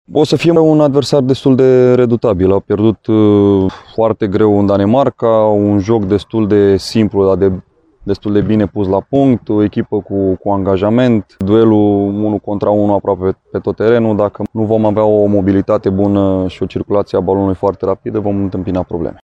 Rădoi a vorbit și despre adversarii din Irlanda de Nord.